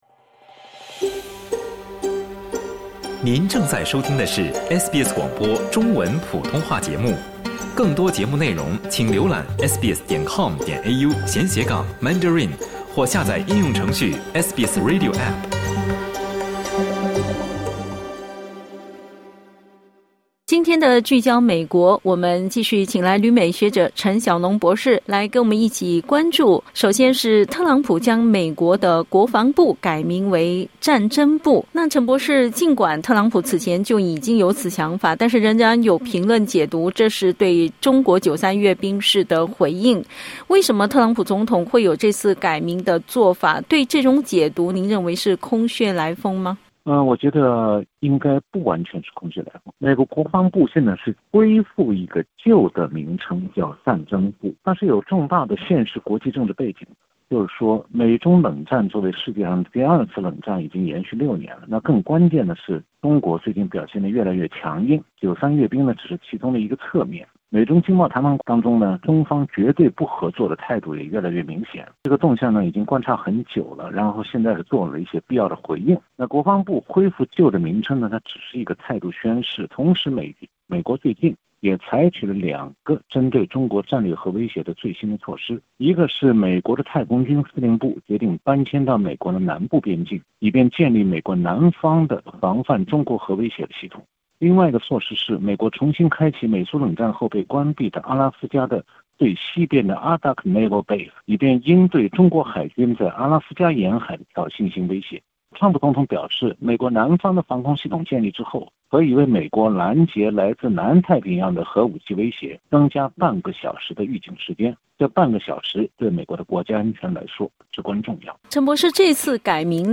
点击音频收听详细采访 采访内容仅为嘉宾观点 欢迎下载应用程序SBS Audio，订阅Mandarin。